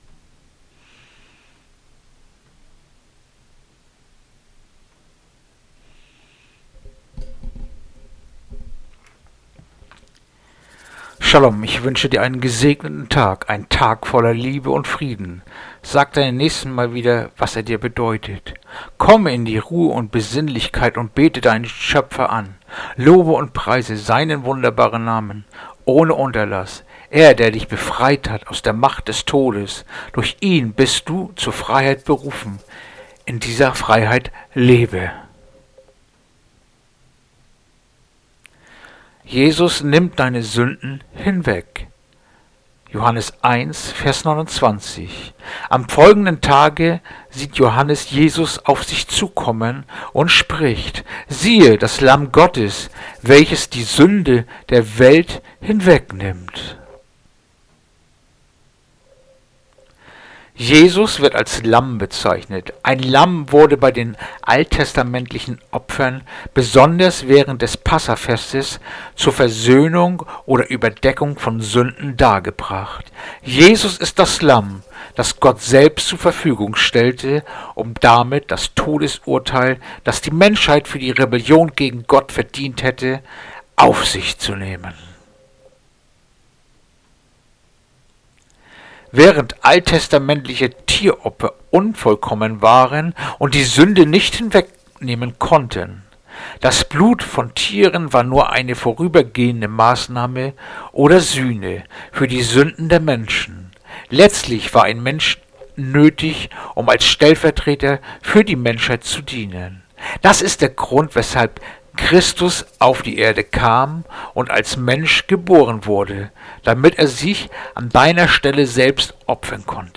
Andacht-vom-12-Mail-Johannes-1-29
Andacht-vom-12-Mail-Johannes-1-29.mp3